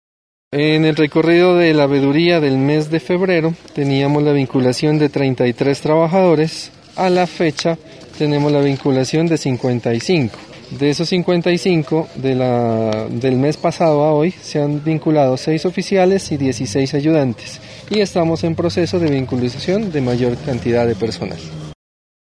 Los integrantes de las veedurías ciudadanas del proyecto de construcción de la plaza de mercado de Sandoná y dos funcionarias de la Administración Municipal visitaron la obra este martes en la tarde.